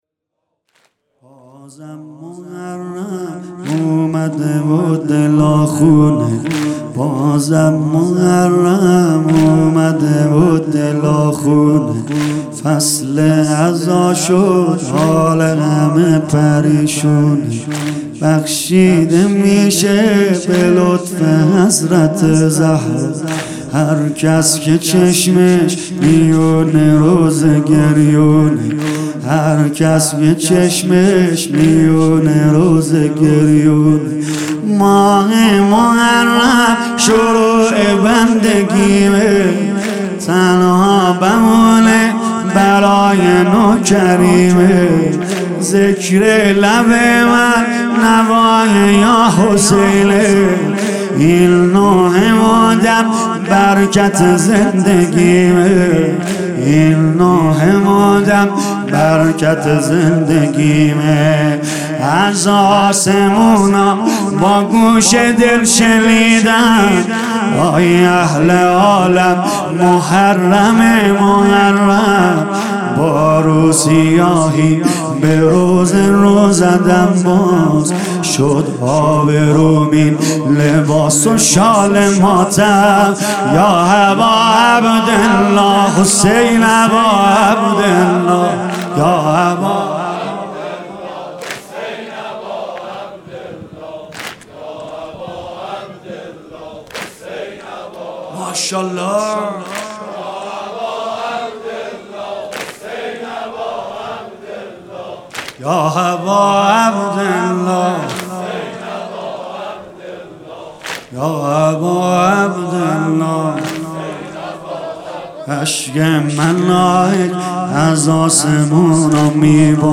شب دوم محرم ۹۹ - هیئت فدائیان حسین